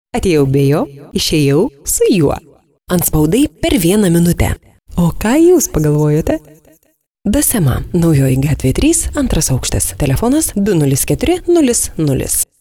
Professionelle litauische Sprecherin für TV / Rundfunk / Industrie / Werbung.
Sprechprobe: Industrie (Muttersprache):
lithuanian female voice over talent